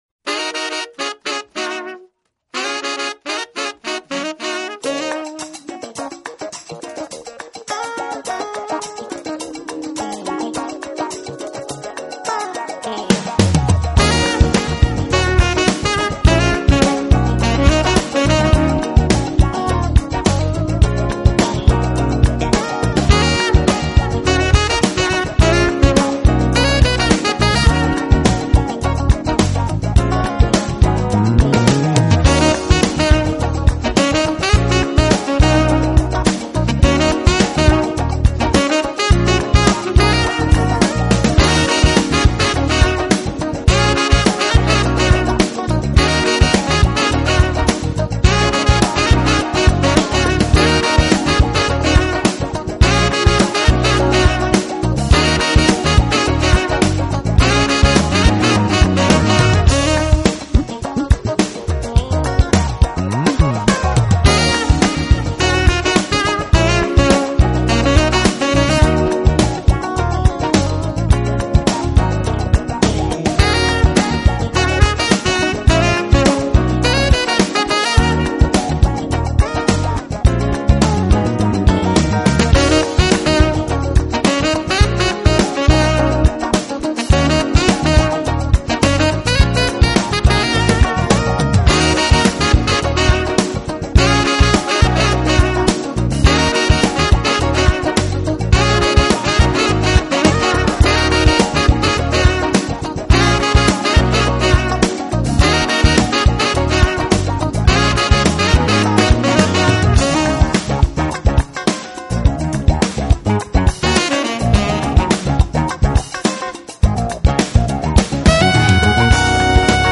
音乐风格：爵士
喜爱把Soul, Funk, 以及Jazz融合在一起来演奏，在他未成名之前他曾和Jeff Lorber